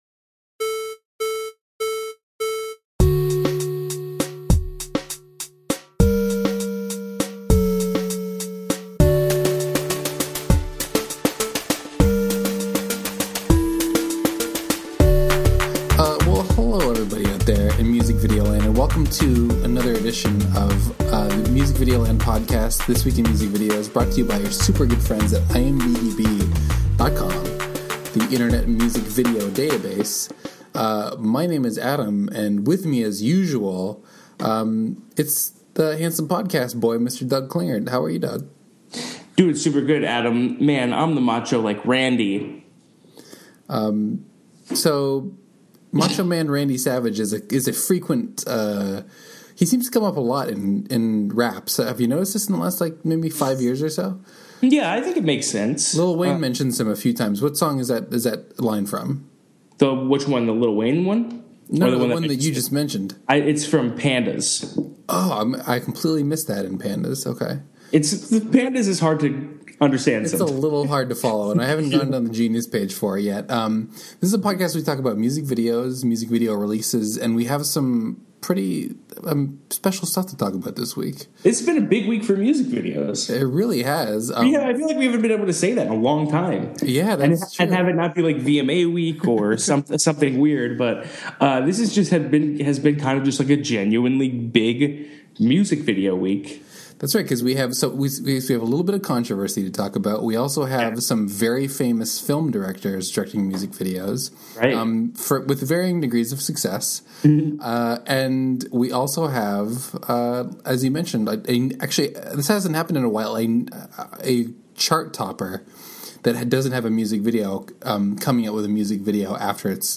A weekly conversation about music video news and new releases.